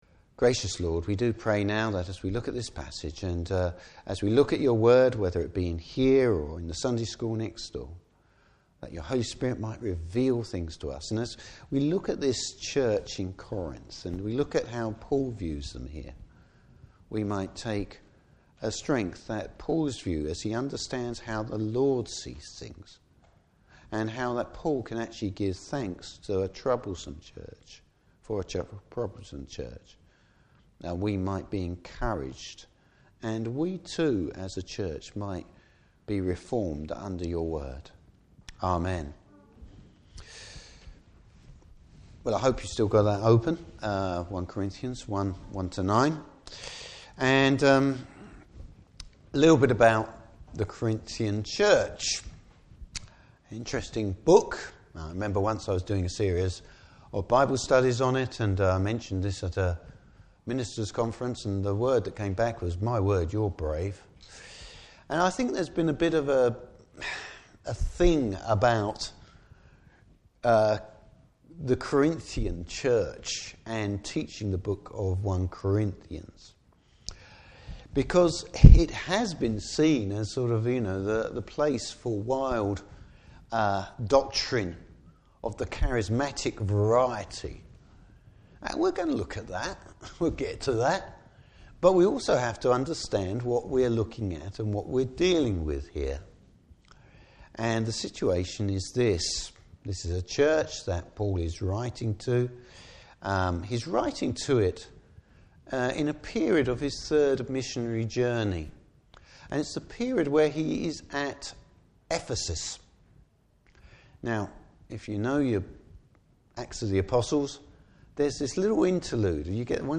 Passage: 1 Corinthians 1:1-9. Service Type: Morning Service God is faithful!